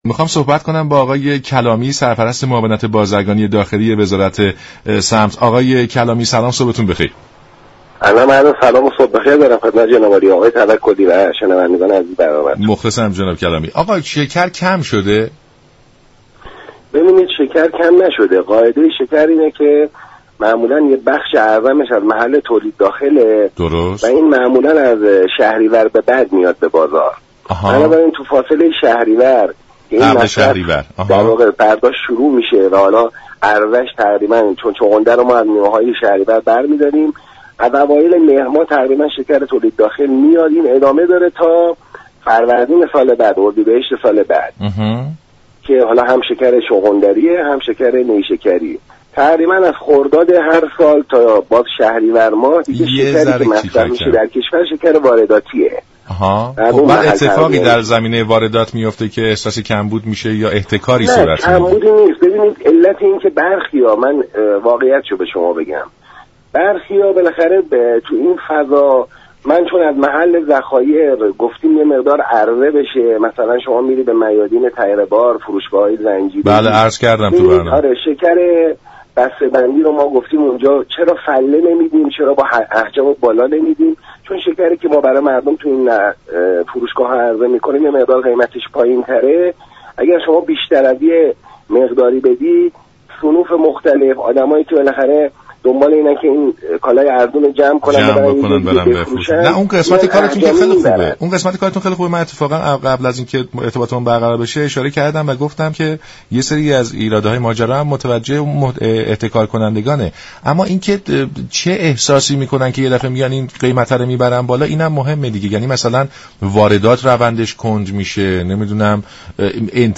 به گزارش شبكه رادیویی ایران، محمدرضا كلامی سرپرست معاونت بازرگانی داخلی وزارت صنعت، معدن و تجارت (صمت) در برنامه سلام صبح بخیر درباره كمبود شكر در بازار گفت: ما دو نوع شكر چغندری و نیشكری داریم و تقریبا از خرداد هر سال تا شهریور شكر وارداتی وجود دارد و كمبودی هم نیست.